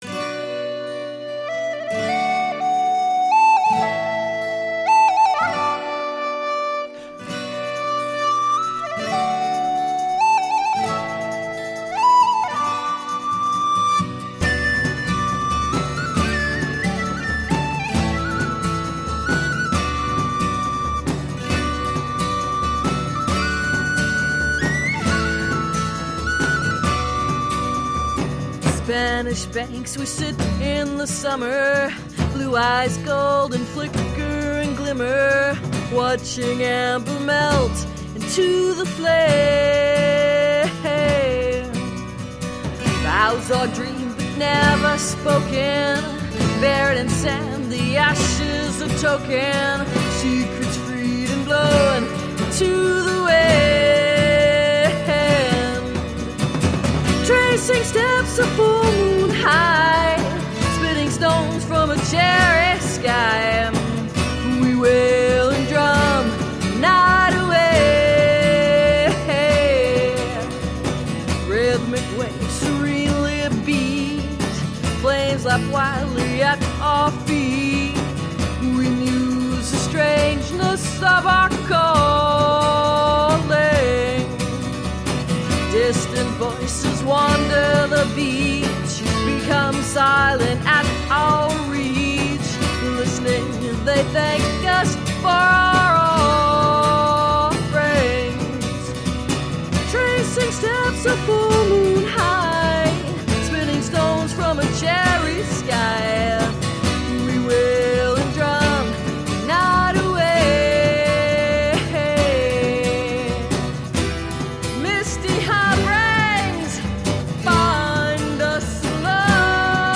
Jazzed up, funk-tinged eclectic pop..
Tagged as: Alt Rock, Rock, Ironic Rock